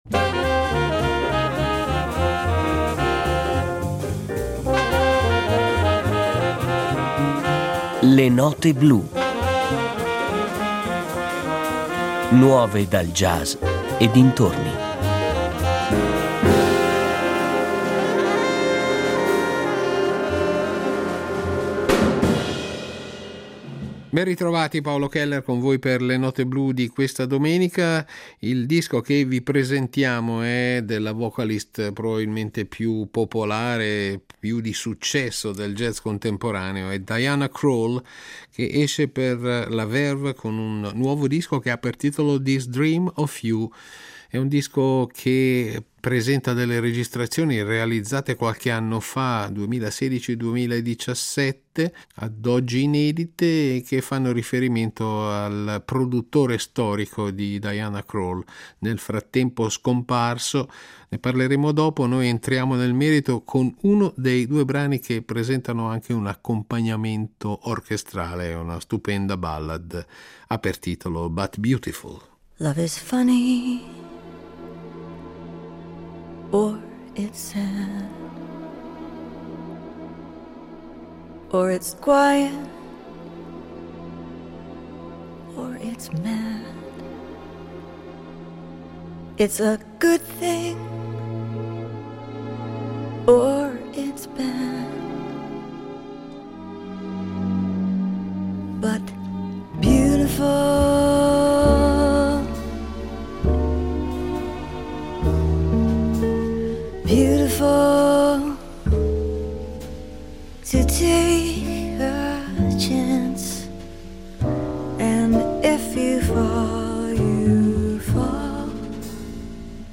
cantante jazz
grandi standard del jazz
al contrabbasso
alla batteria
alla chitarra